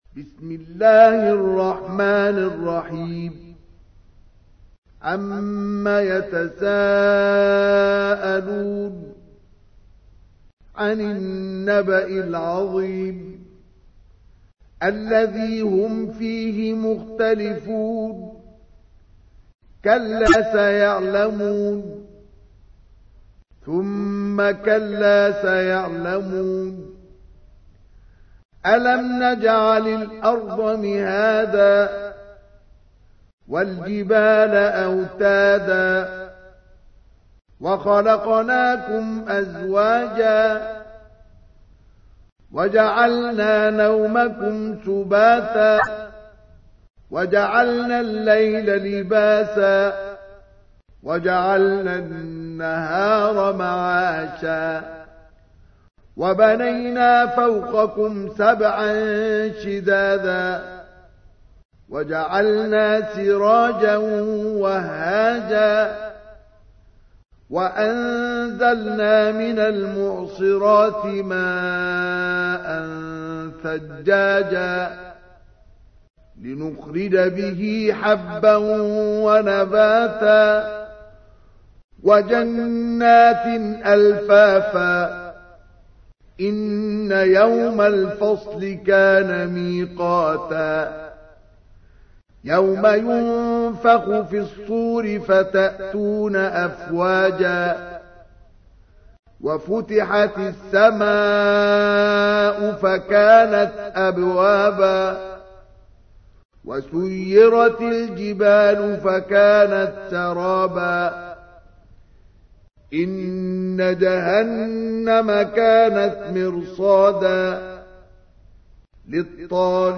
تحميل : 78. سورة النبأ / القارئ مصطفى اسماعيل / القرآن الكريم / موقع يا حسين